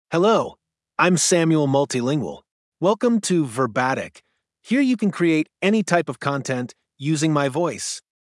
MaleEnglish (United States)
Samuel Multilingual is a male AI voice for English (United States).
Voice sample
Samuel Multilingual delivers clear pronunciation with authentic United States English intonation, making your content sound professionally produced.